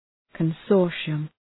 Προφορά
{kən’sɔ:rʃıəm}